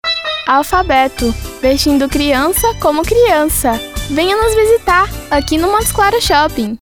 Commercial Voice Over Talent for Radio & TV Ads